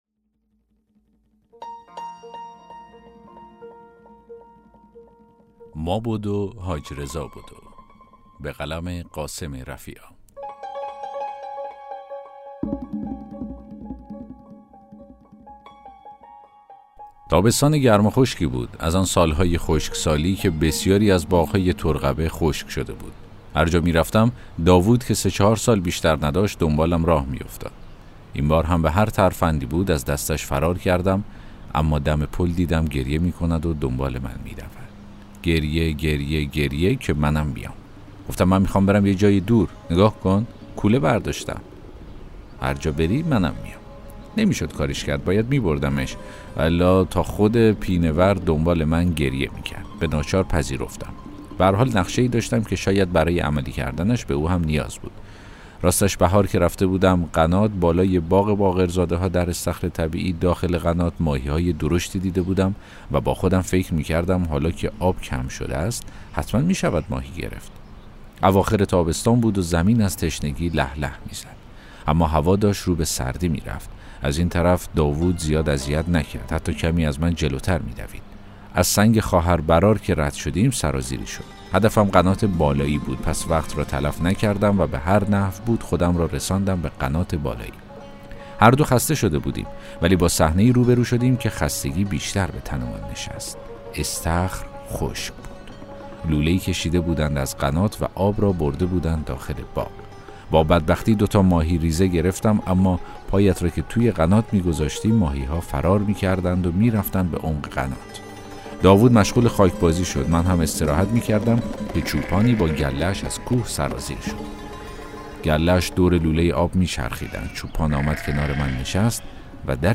داستان صوتی: ما بدو، حاج‌رضا بدو